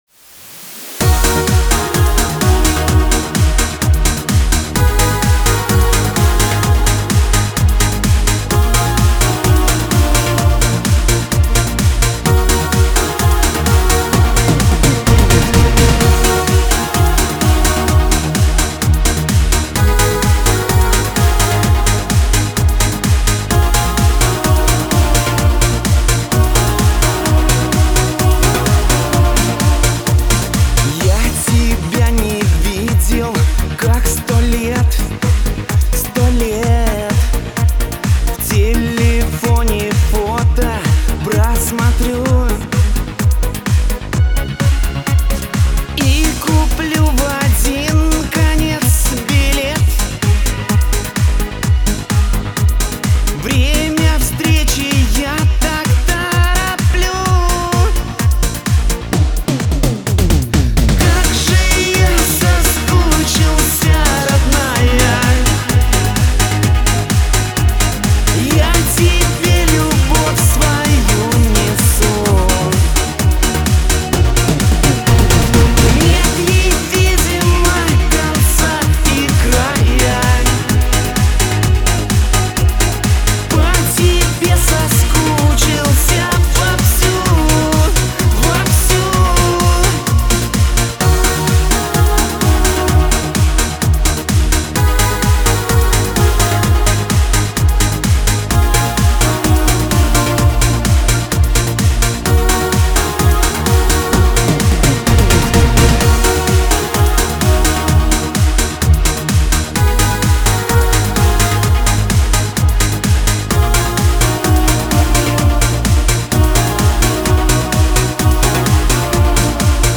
Лирика , надежда , грусть